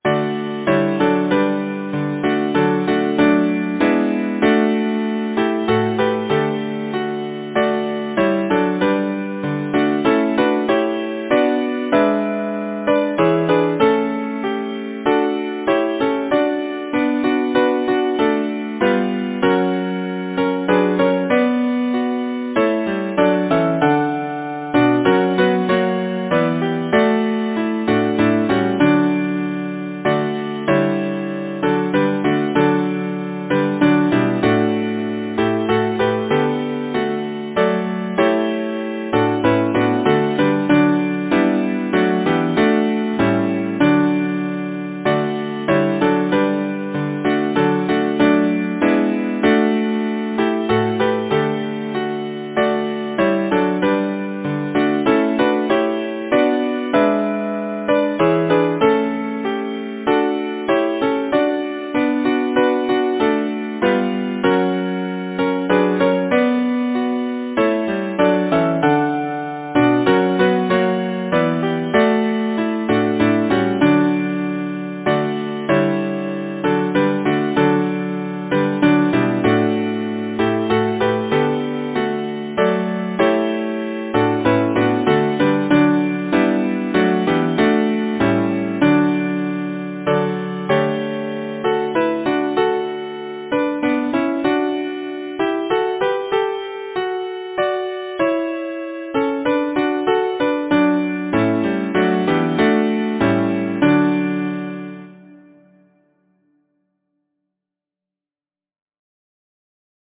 Title: In Summer-time Composer: John James Dawson Lyricist: Meta Crowcreate page Number of voices: 4vv Voicing: SATB Genre: Secular, Partsong
Language: English Instruments: A cappella